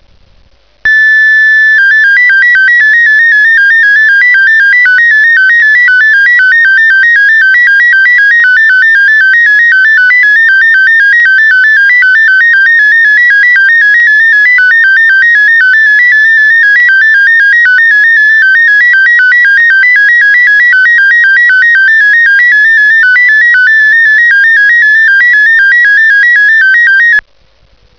Espectro del modo Ros 8/500.
Escuche como suena un CQ en este modo: